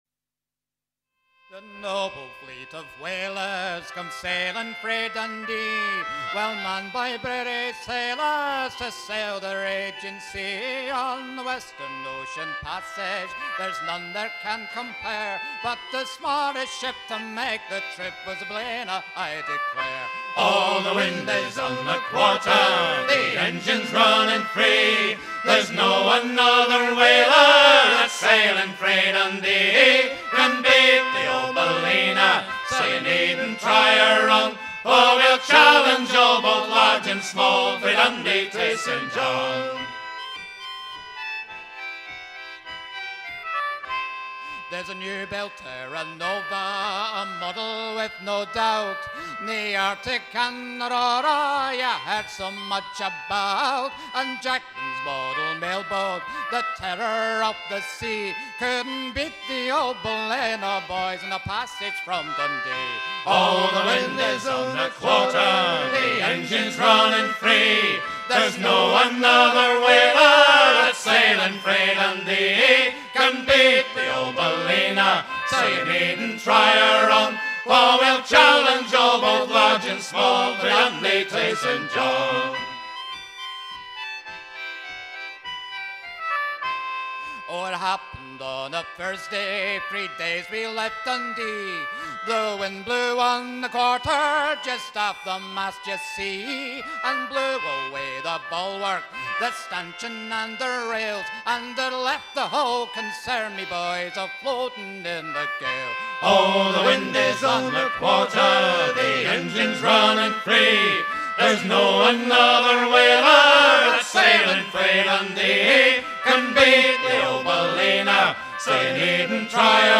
Editeur Note chant de gaillard d'avant.
Genre strophique
Pièce musicale éditée